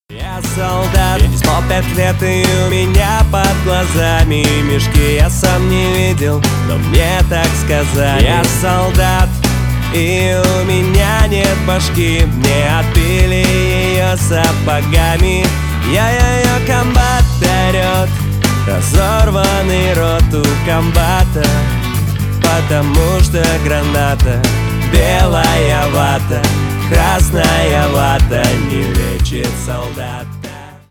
Рок Металл
кавер